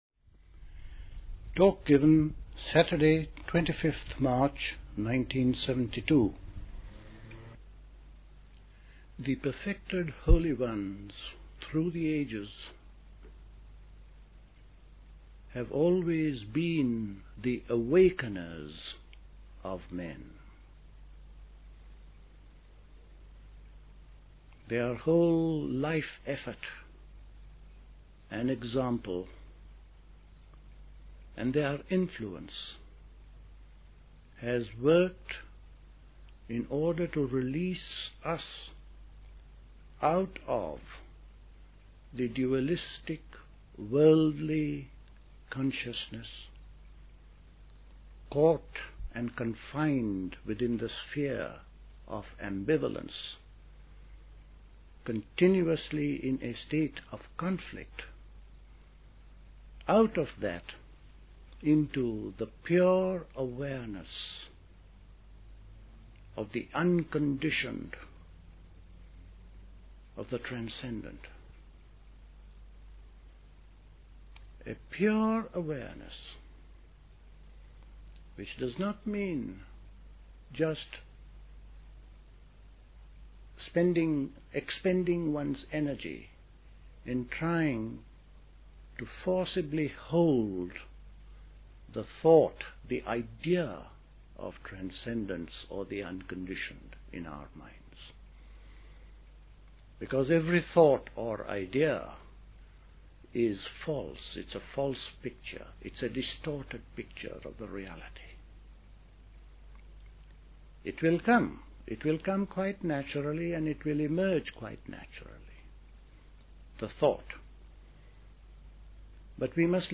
A talk
at Dilkusha, Forest Hill, London on 25th March 1972